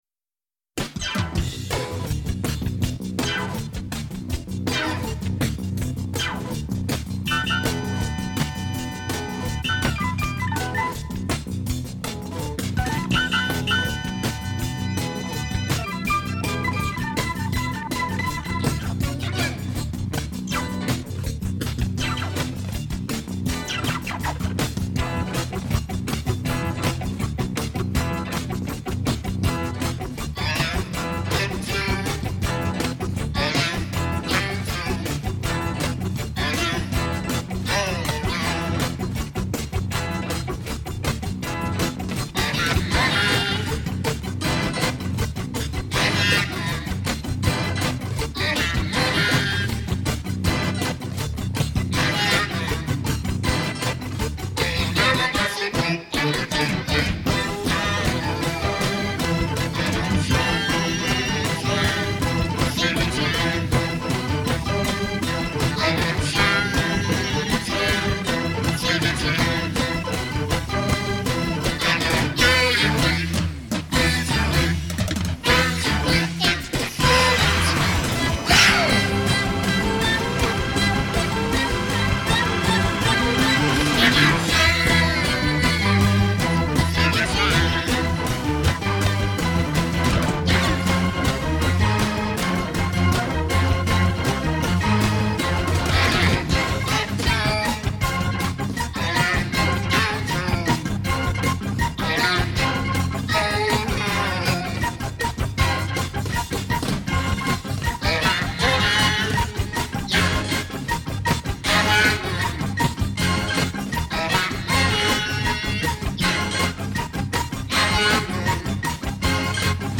Pour enchainement (modifié en Mi)